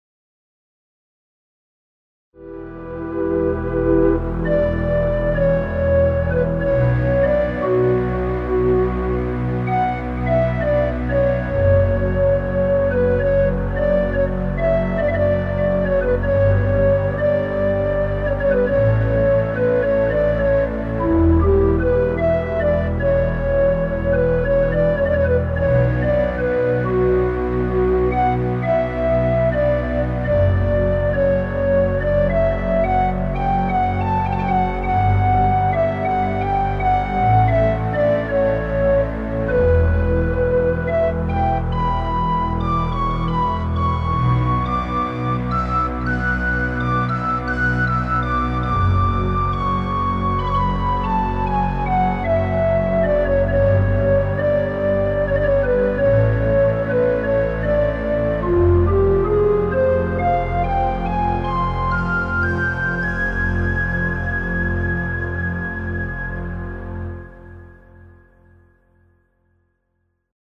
Largo [40-50] tristesse - flute - pensif - paysage - neant